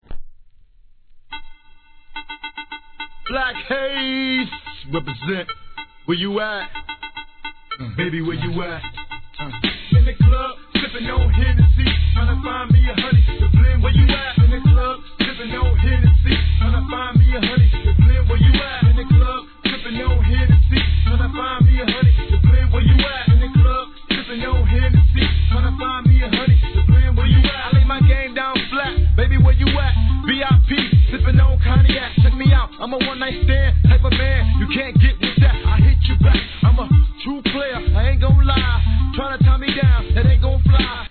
G-RAP/WEST COAST/SOUTH
マイアミ産スペイシーファンク！！